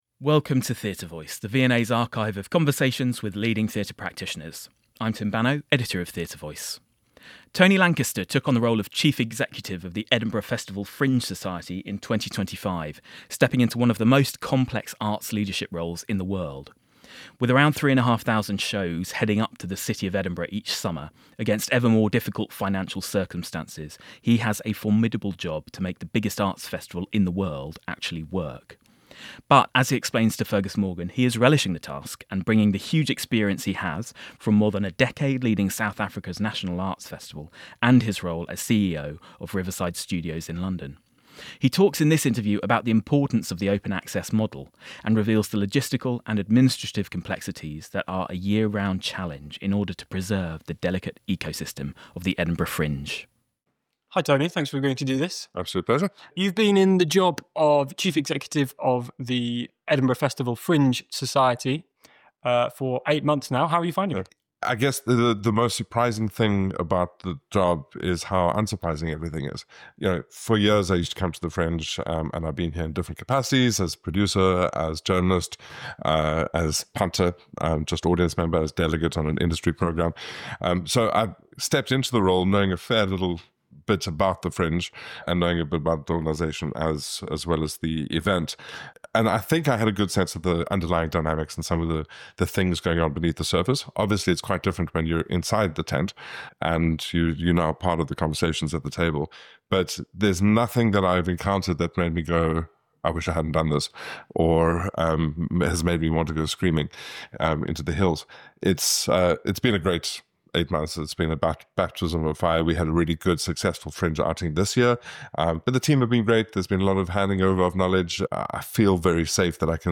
INTERVIEW
Recorded in person in Edinburgh, 9th December, 2025 Follow us Twitter Facebook You Tube RSS Listen to our podcast Subscribe via iTunes Subscribe to our Podcast Featured Categories 20th-Century Drama Actors and Performers Directors Feminism